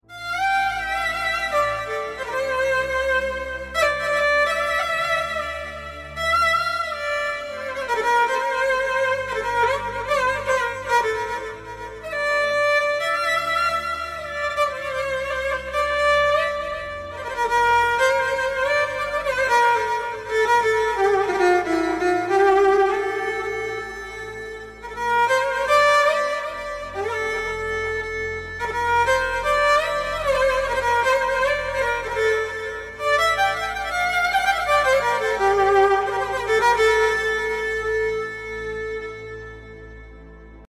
كمان كورج M3 | KORG Sound Effects Free Download